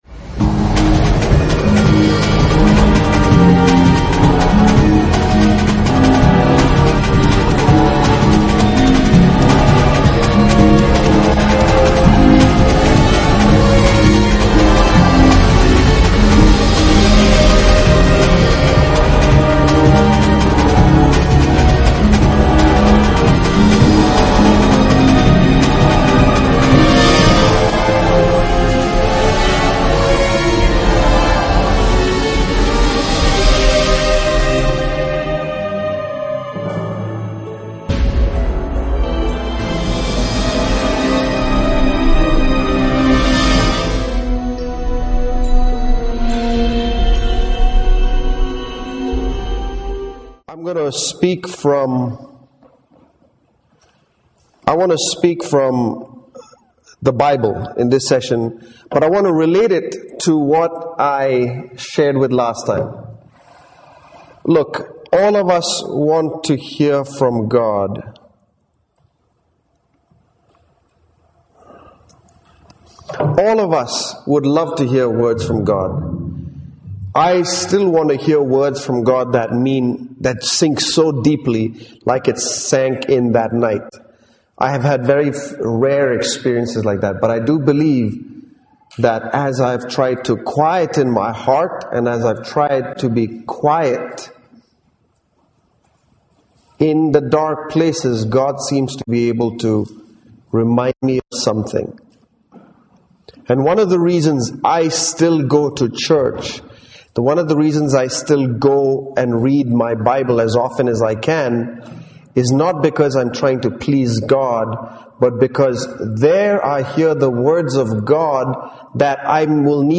- 1 Cor 12:25 These messages were given at the CFC Youth Camp in December 2008 To view a message, click on the message title.